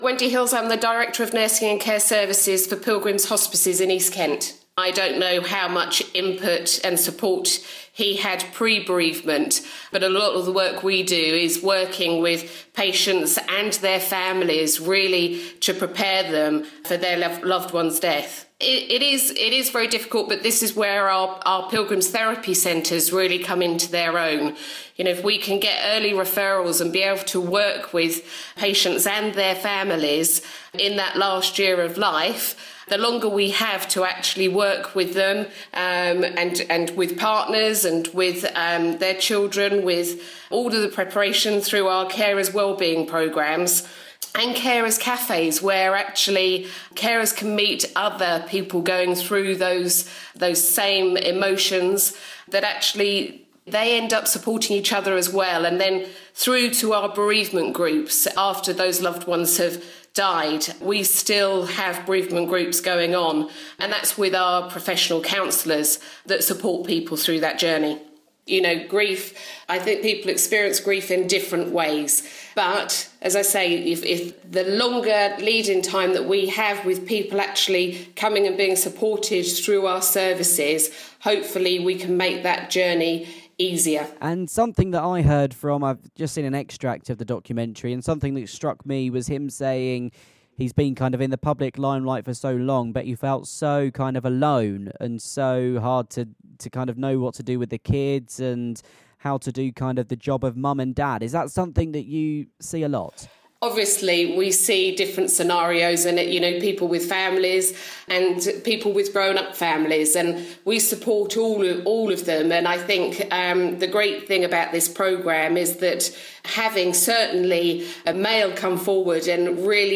kmfm news / INTERVIEW